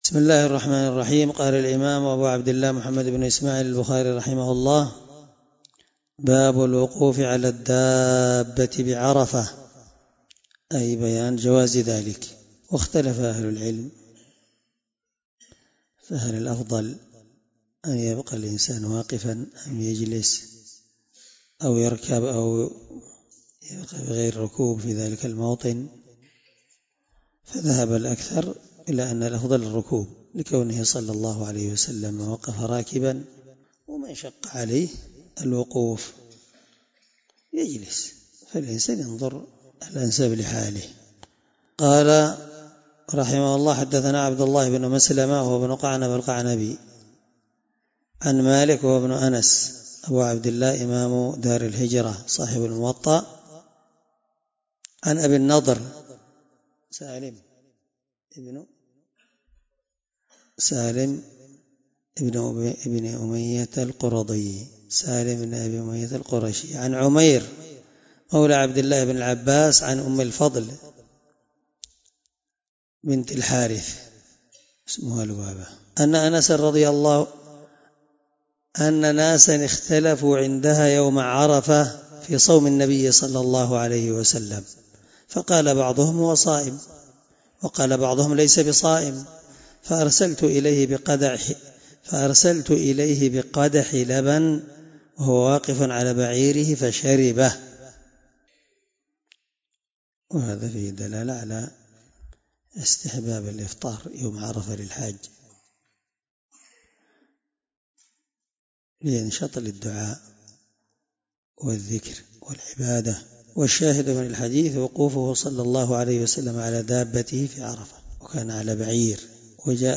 الدرس61 من شرح كتاب الحج حديث رقم(1661-1663 )من صحيح البخاري